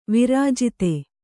♪ virājite